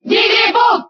Category:Crowd cheers (SSBB) You cannot overwrite this file.
Jigglypuff_Cheer_Spanish_SSBB.ogg.mp3